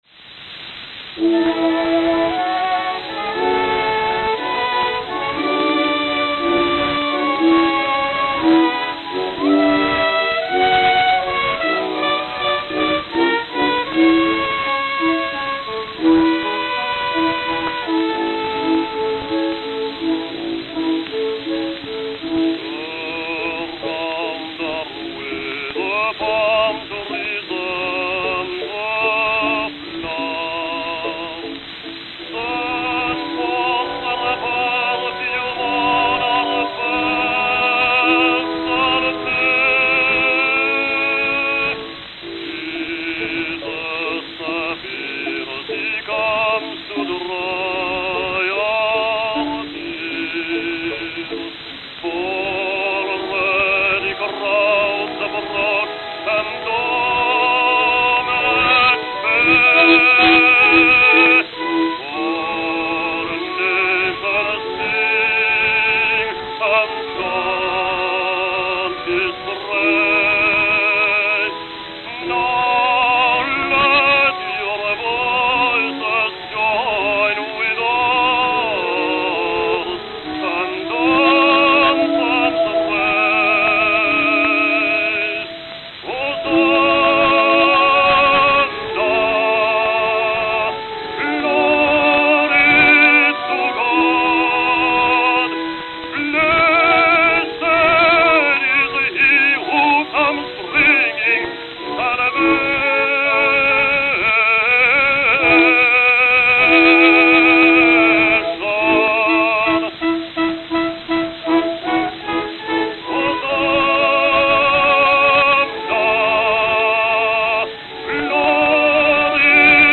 Philadelphia, Pennsylvania (?)